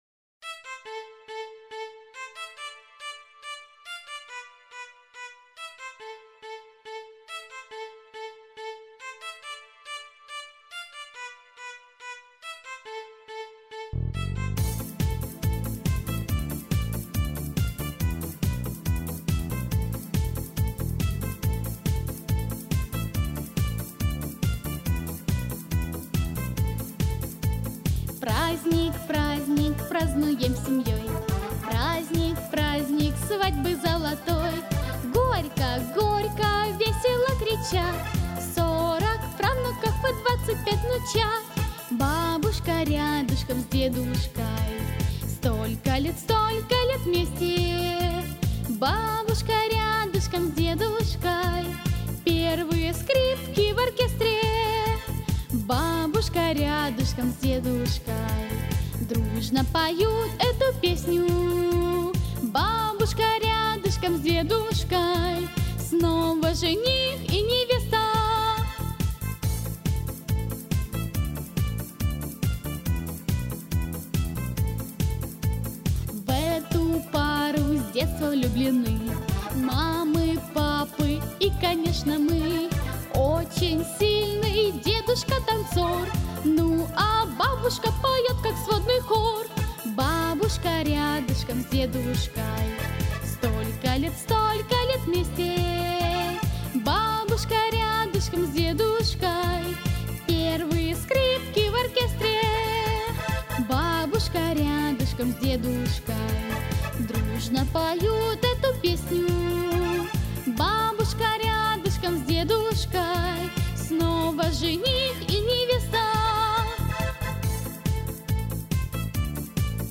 В утешение... симпатичный минус